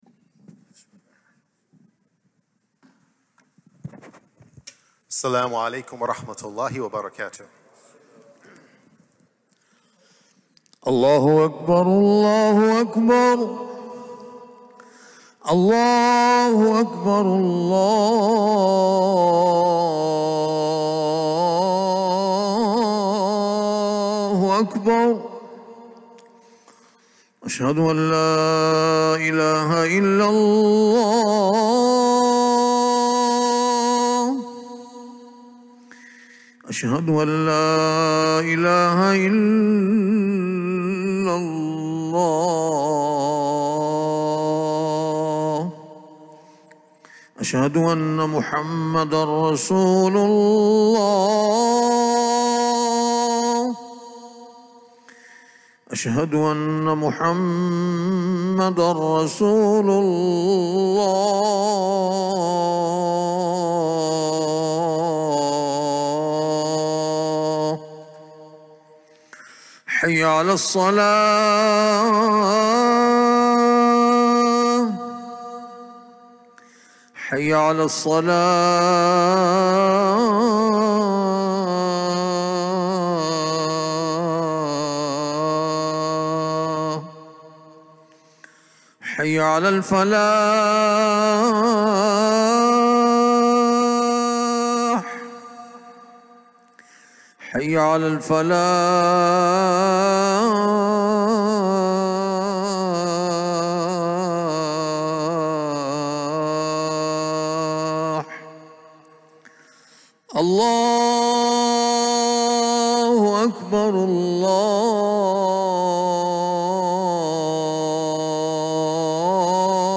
Friday Khutbah - "Happily Ever After!"